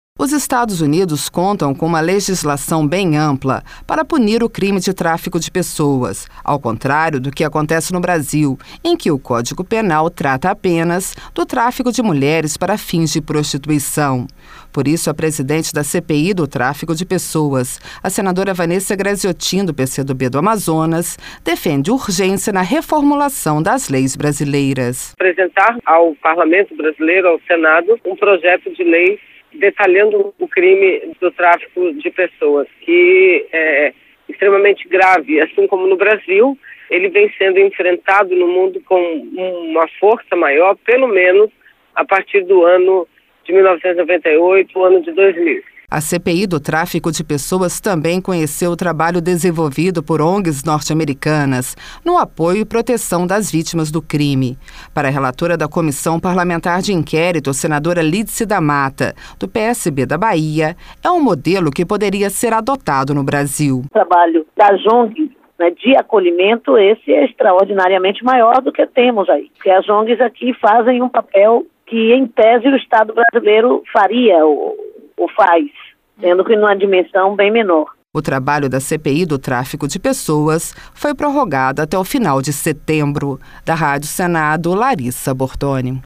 Para a relatora da comissão parlamentar de inquérito, senadora Lídice da Matta, do PSB da Bahia, é um modelo que poderia ser adotado no Brasil.